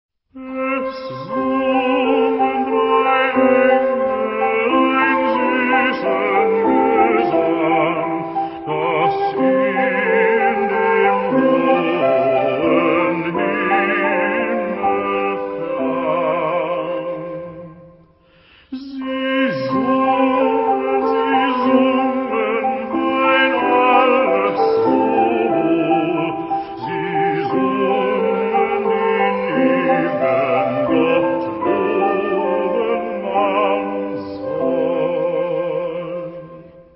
Época : Siglo 20
Género/Estilo/Forma: Sagrado ; Canon
Tipo de formación coral: SA  (2 voces Coro femenino )
Tonalidad : do mayor